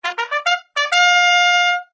UI_LevelUp.ogg